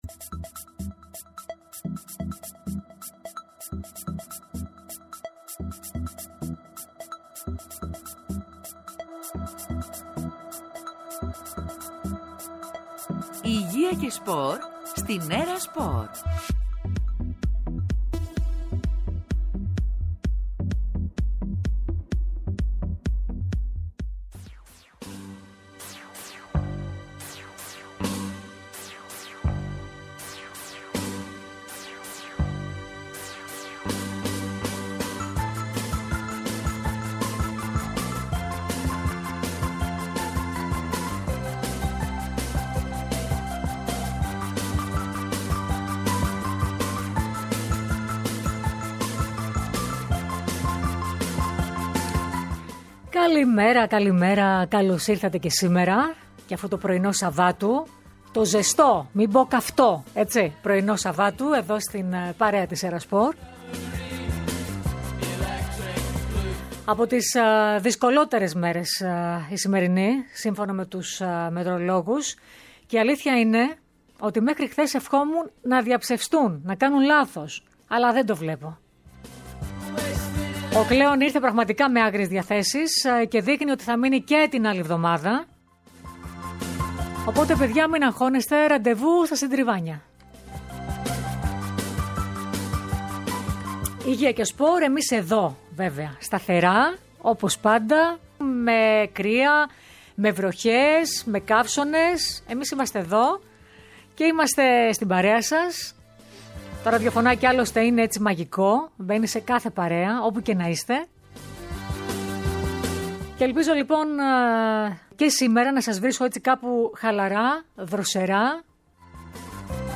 Ένα μεγάλο αστέρι του ελληνικού αθλητισμού και του τριπλούν, την ολυμπιονίκη, παγκόσμια πρωταθλήτρια και πρωταθλήτρια Ευρώπης, Όλγα Βασδέκη φιλοξενήσαμε σήμερα στην ΕΡΑΣΠΟΡ!! Μας μίλησε για την νέα της μεγάλη αγάπη, τον μηχανοκίνητο αθλητισμό και τον σύλλογο Ι.ΔΟ.ΡΟ, που έχει ιδρύσει, ενώ αναφέρθηκε στα χρόνια του πρωταθλητισμού, στο μεγάλο της απωθημένο, στο διάστημα προσαρμογής, μετά το τέλος του πρωταθλητισμού, αλλά και για το ενδεχόμενο να ασχοληθεί με την προπονητική.